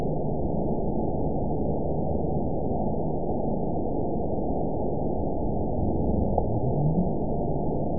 event 921657 date 12/14/24 time 21:56:38 GMT (4 months, 3 weeks ago) score 9.61 location TSS-AB03 detected by nrw target species NRW annotations +NRW Spectrogram: Frequency (kHz) vs. Time (s) audio not available .wav